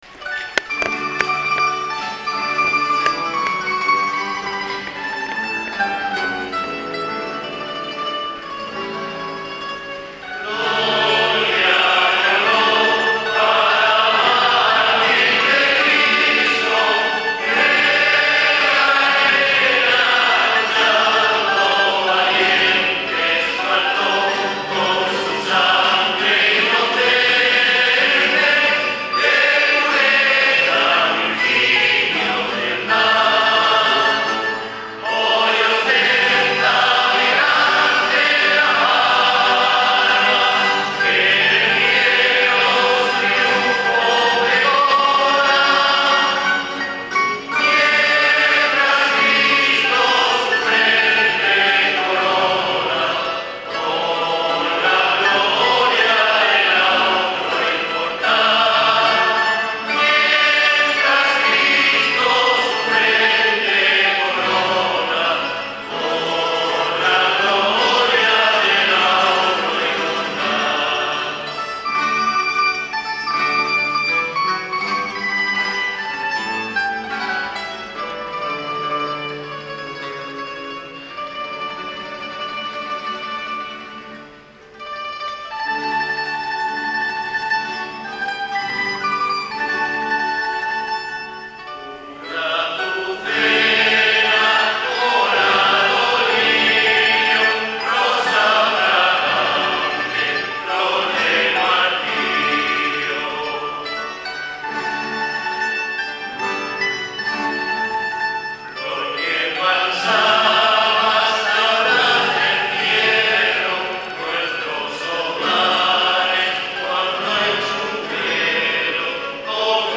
"Los Carrasqueados" y la Tuna de Totana  han dado la bienvenida a la Patrona, Santa Eulalia de Mérida, durante la serenata que se ha celebrado coincidiendo con su primera noche en la parroquia de Santiago El Mayor.
Los dos grupos han protagonizado este año la serenata, interpretando cada uno numerosas piezas musicales; y con la asistencia de numeroso público y autoridades civiles y religiosas.
serenata